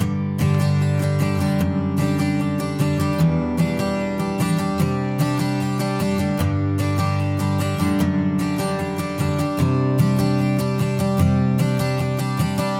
标签： 150 bpm Acoustic Loops Guitar Acoustic Loops 2.15 MB wav Key : G Pro Tools